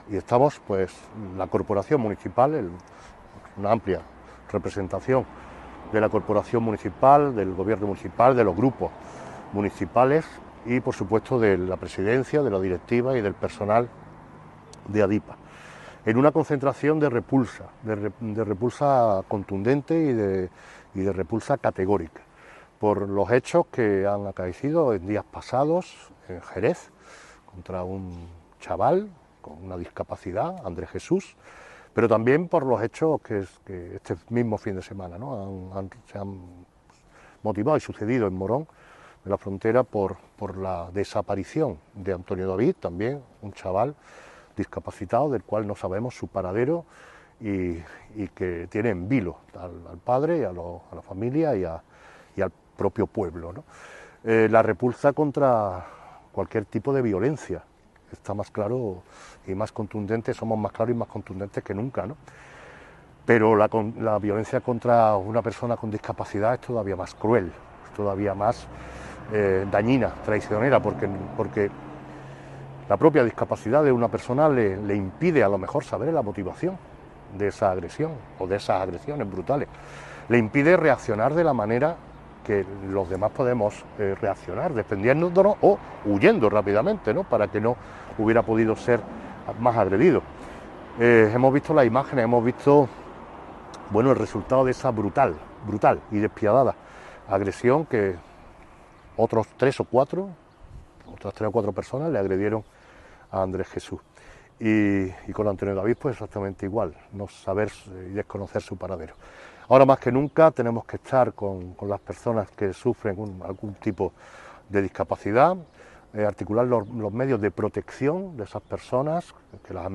Integrantes del Equipo de Gobierno y del resto de la Corporación Municipal así como representantes de la junta directiva y de la plantilla de ADIPA han estado presentes también en este simbólico acto desarrollado a las puertas de la sede de ADIPA de carretera de Córdoba.
Cortes de voz